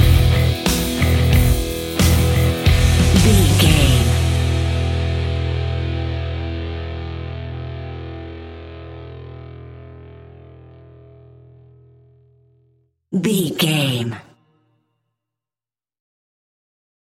Aeolian/Minor
Slow
hard rock
heavy metal
distortion
Rock Bass
Rock Drums
heavy drums
distorted guitars
hammond organ